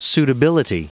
Prononciation du mot suitability en anglais (fichier audio)
Prononciation du mot : suitability